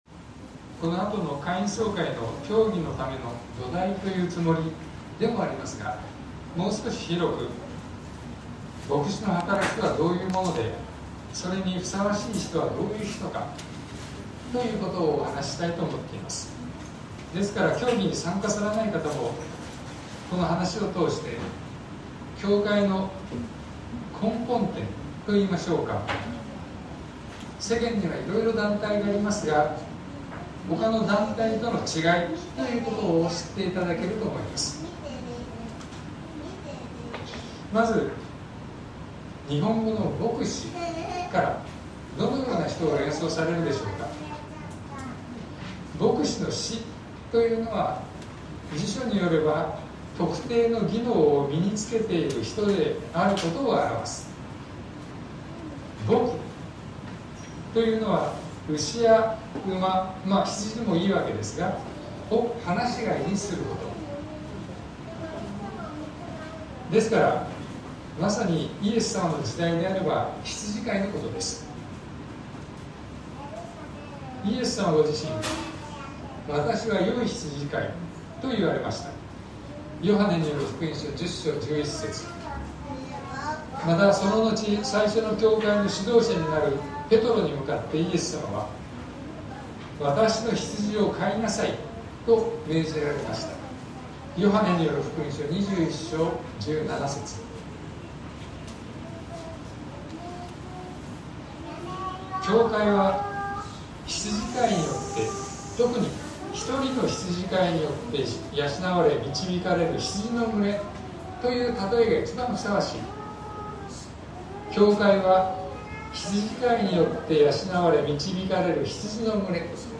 2024年07月28日朝の礼拝「牧師とは？」東京教会
説教アーカイブ。